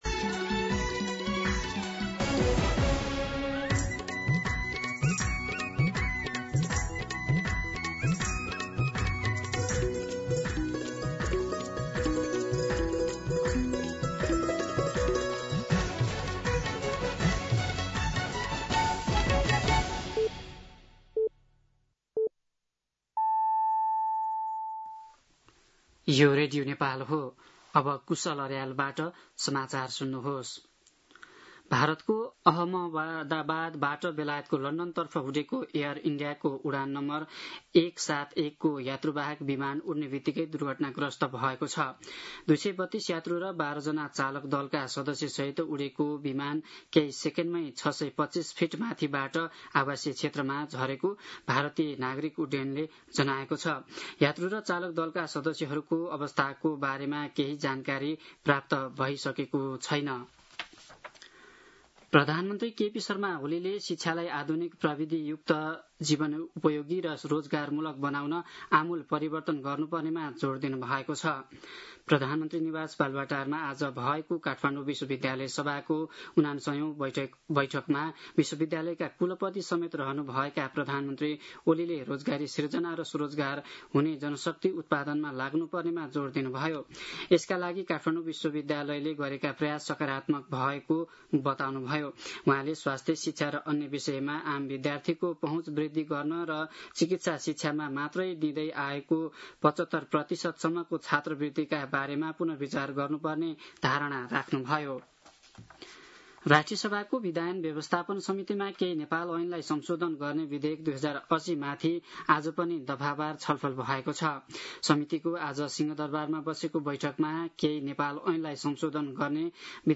दिउँसो ४ बजेको नेपाली समाचार : २९ जेठ , २०८२
4-pm-Nepali-News.mp3